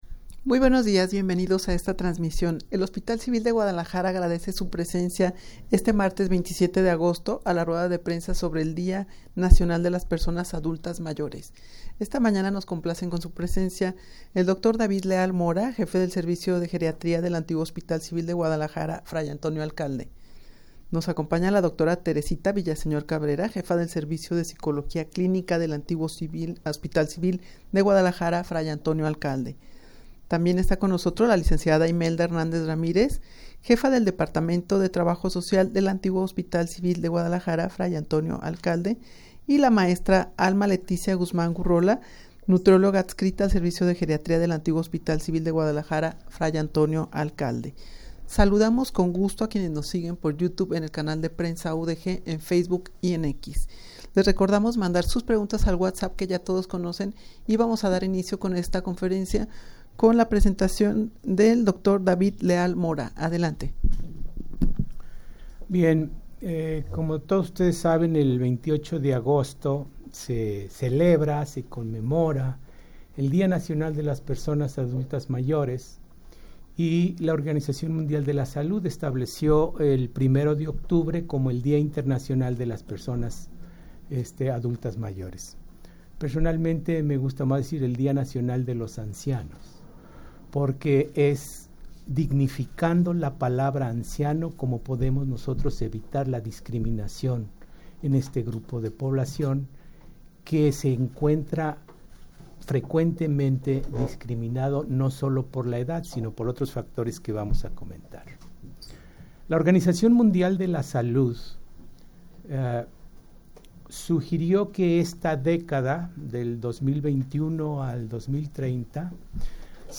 Audio de la Rueda de Prensa
rueda-de-prensa-sobre-el-dia-nacional-de-las-personas-adultas-mayores-que-se-llevara-a-cabo-el-28-de-agosto.mp3